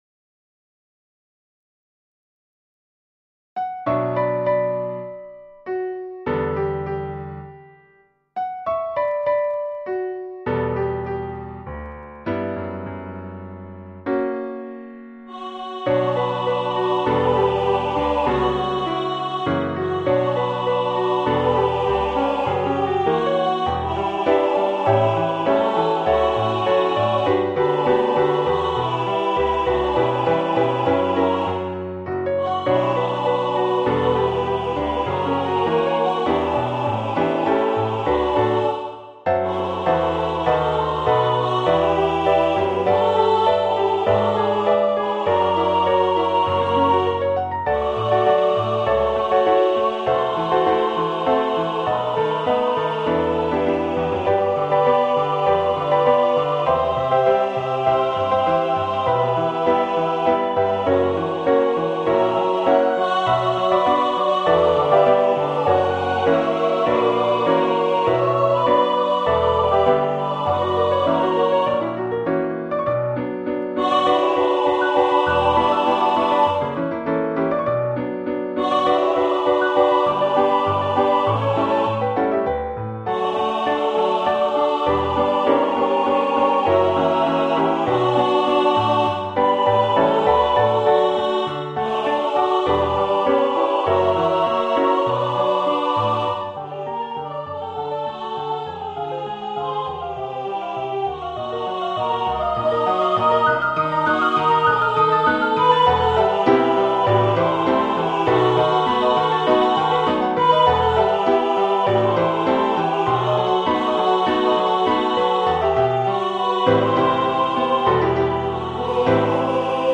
Ноты для фортепиано, вокальная партитура.